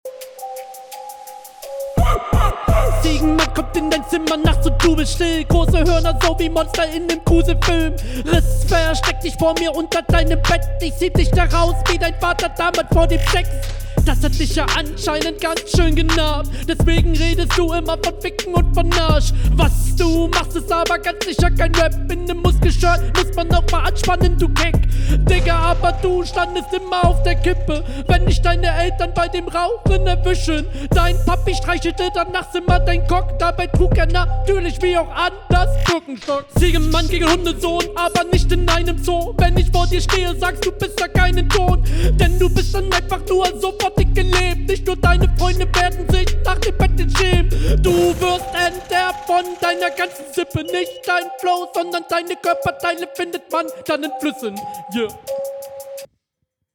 Stimmlich aggressiv aber gleichzeitig auch cool.
Flow und Stimme kommen richtig cool.